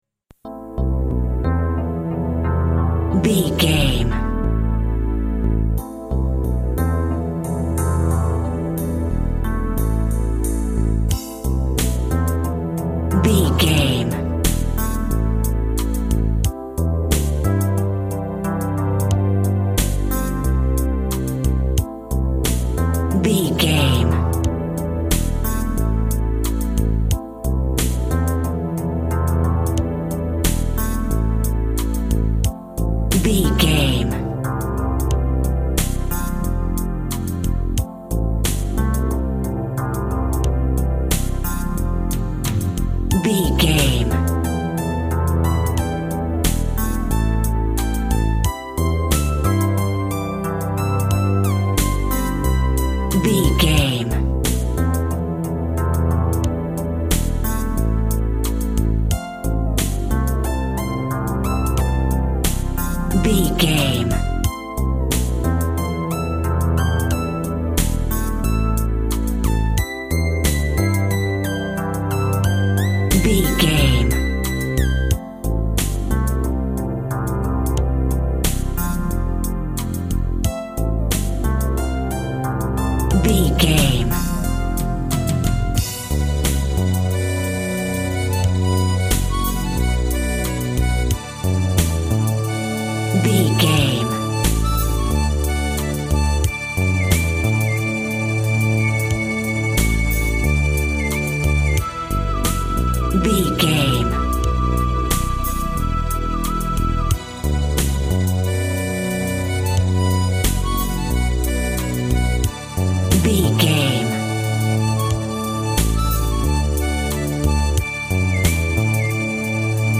Eighties Cop Music Cue.
Aeolian/Minor
groovy
smooth
futuristic
uplifting
drums
synthesiser
Retro
cheesy
electronic
synth bass
synth lead